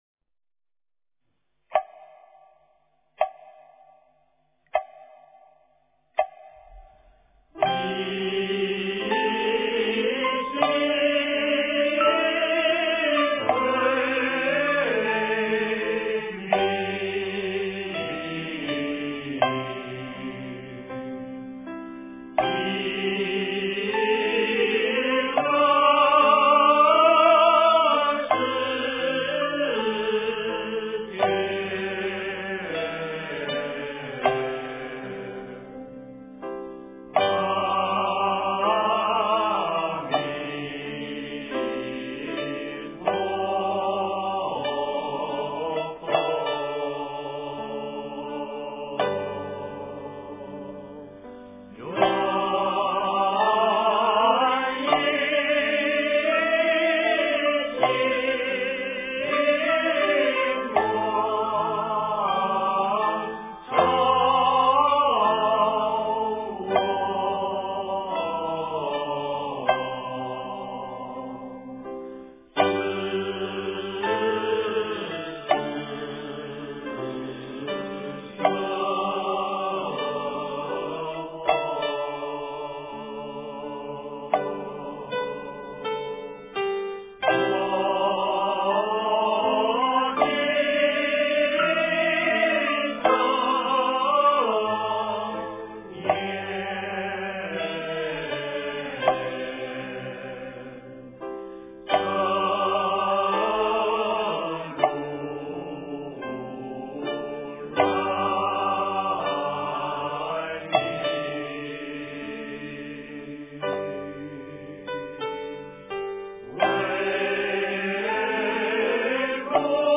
净土文--佛教唱颂编 经忏 净土文--佛教唱颂编 点我： 标签: 佛音 经忏 佛教音乐 返回列表 上一篇： 朝山礼拜--圆光佛学院众法师 下一篇： 南无消灾延寿药师佛圣号--中国佛学院 相关文章 心经-日文版--R&B 心经-日文版--R&B...